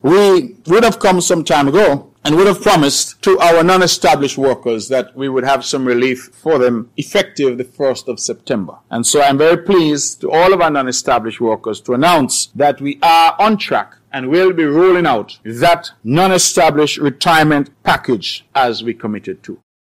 Premier of Nevis, the Hon. Mark Brantley, speaking on August 30th